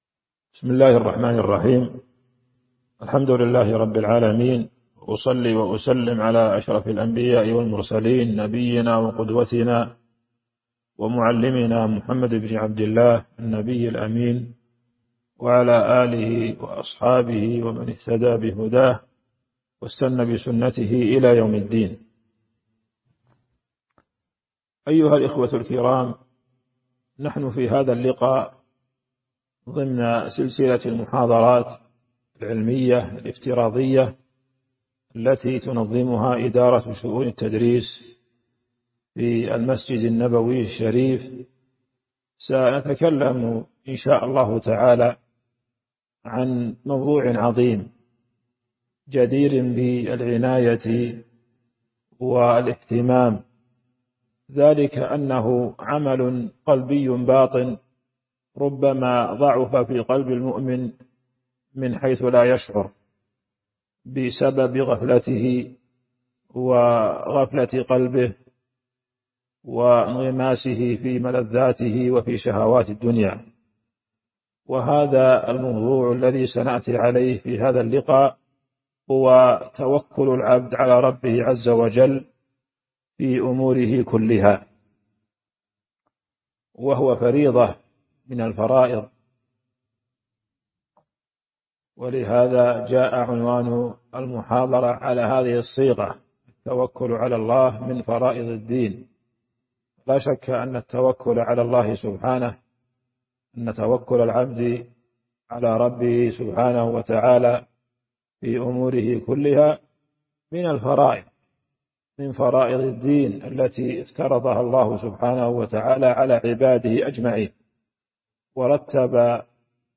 تاريخ النشر ٢٥ رمضان ١٤٤٢ هـ المكان: المسجد النبوي الشيخ